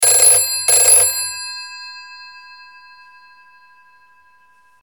Ringtones
Young people these days don’t even know what a proper phone should sound like.
How would he class someone who makes their own ring tone (Googles for and downloads source files, gets audio editor software, mixes the sound file, bluetooths it to their handset), but wants the sound to be the same as phones he remembers from forty+ years ago?
britphone.mp3